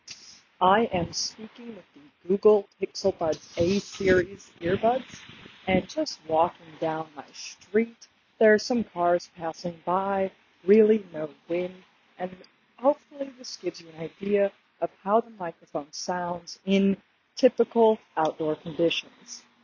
However, when you speak from a somewhat noisy place, your conversation partner will have a hard time hearing you. In the demo below, my voice almost completely drops out in the intro. The buds were trying to suppress background noise but hushed my voice in the process.
Google Pixel Buds A-Series microphone demo (Non-standardized):
Google-pixel-buds-A-Series-microphone-demo-nonstandardized.mp3